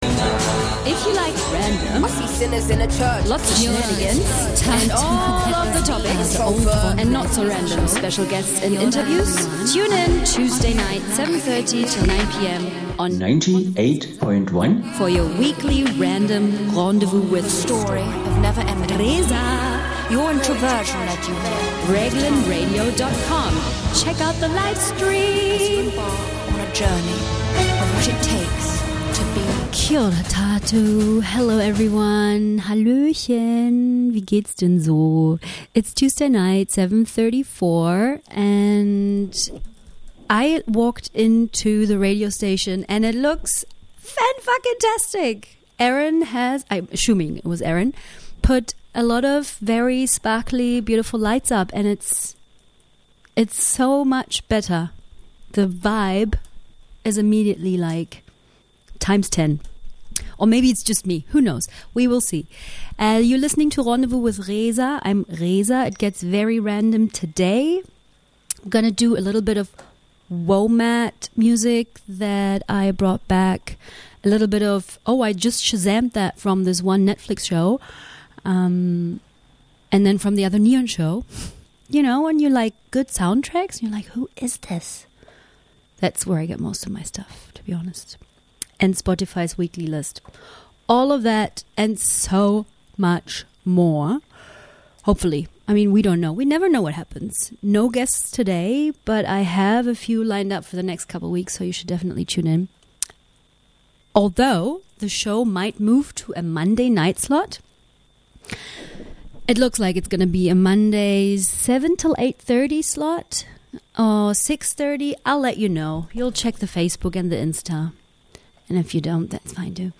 randomness at its best: Hip Hop, electro Pop, folk, world music from WOMAD (whatever that genre is supposed to explain?) and even a classical clash.